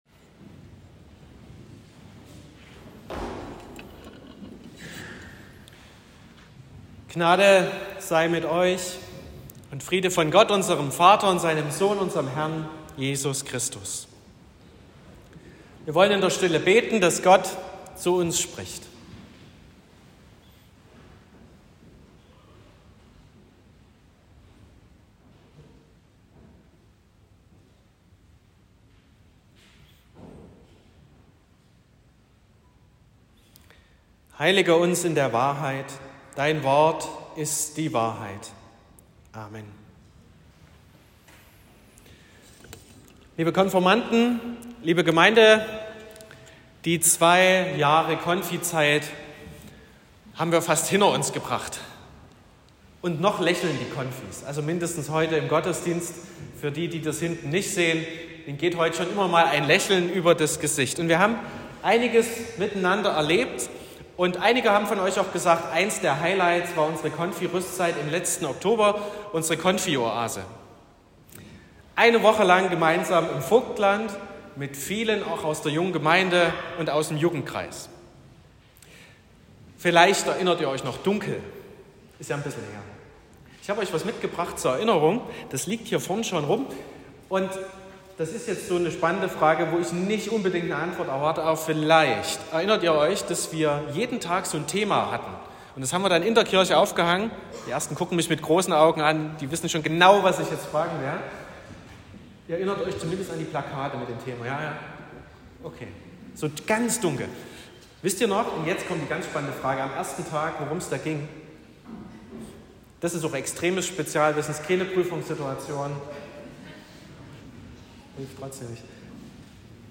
14.04.2024 – Konfirmationsgottesdienst
Predigt (Audio): 2024-04-14_Komm_zum_Vater.m4a (8,9 MB)